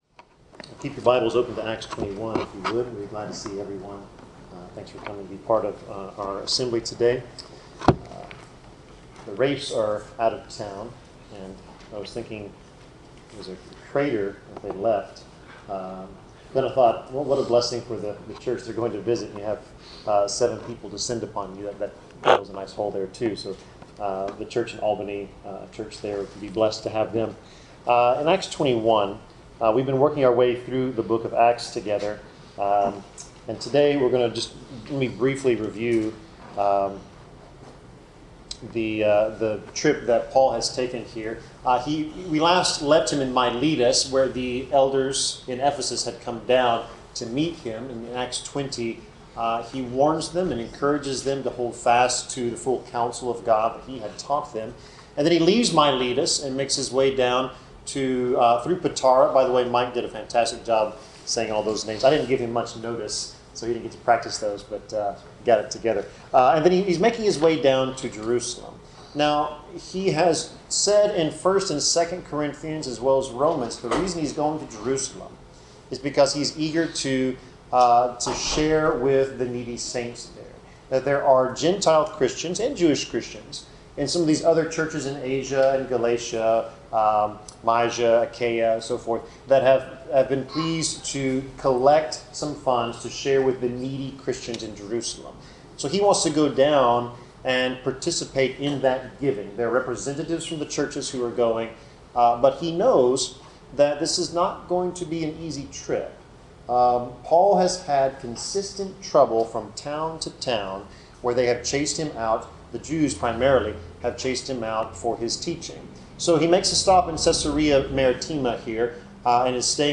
Passage: Acts 21:1-14 Service Type: Sermon